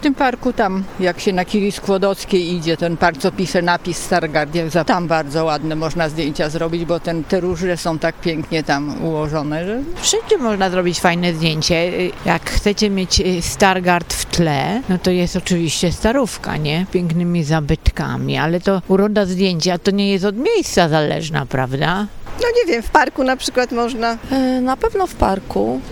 Mieszkańcy Stargardu podzielili się z nami gdzie można zrobić fajne zdjęcia w mieście.